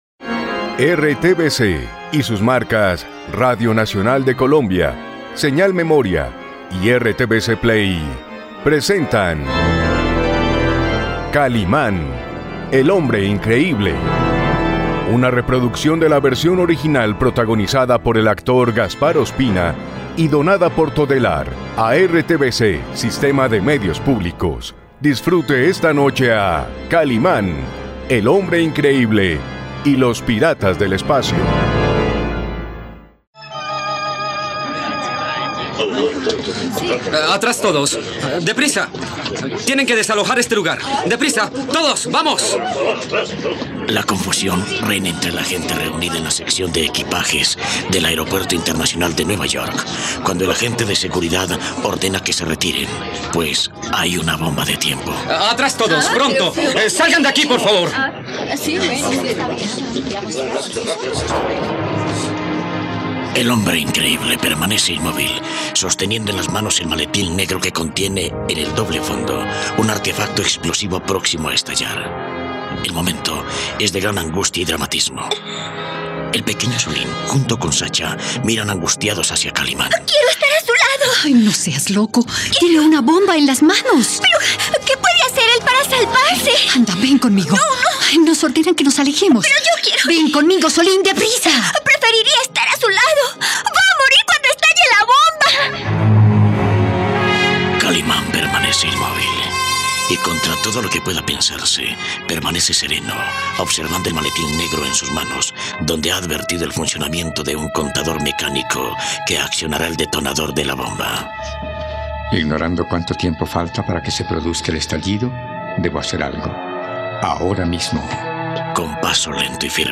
No te pierdas la radionovela de Kalimán y los piratas del espacio aquí, en RTVCPlay.